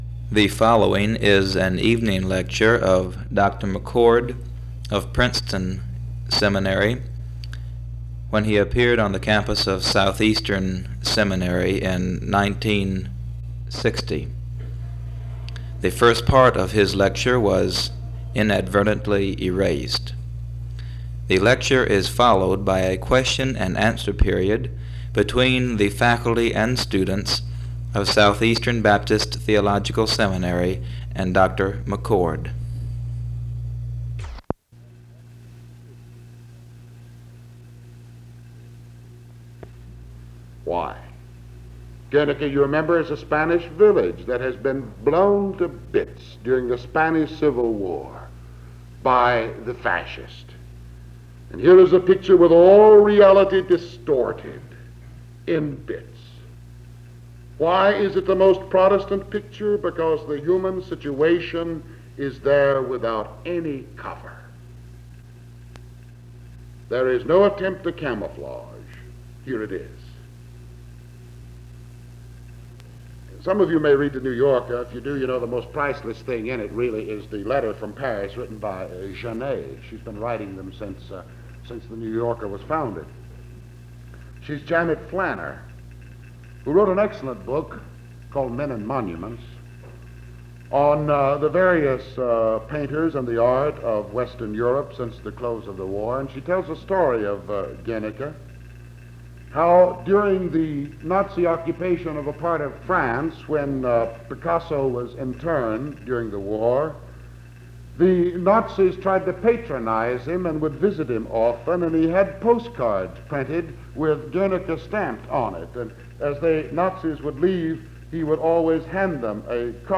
In Collection: SEBTS Chapel and Special Event Recordings